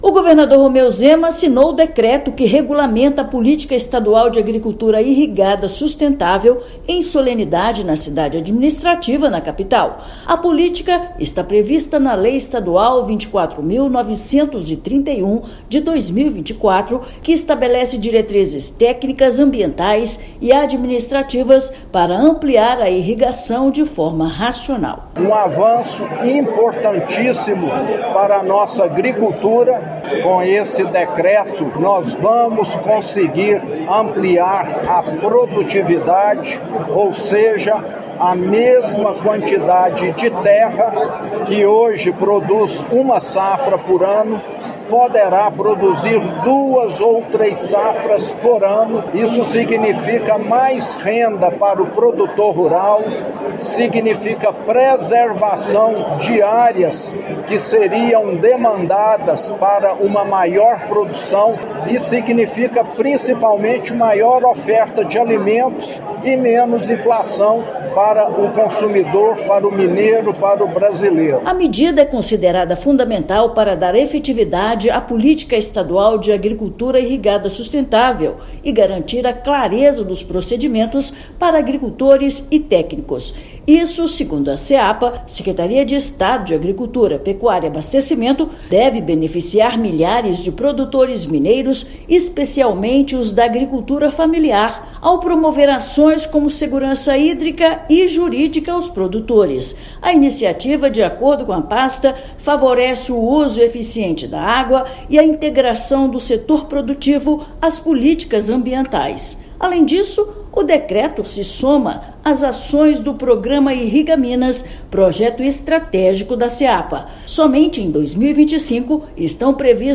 Inédita, norma garante segurança jurídica, produtividade agrícola e preservação ambiental no estado. Ouça matéria de rádio.